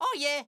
Voice line from Mario saying "Oh yeah!" in Super Mario Bros. Wonder
Voice_Mario_GoalGreetBloom_02_1.wav.mp3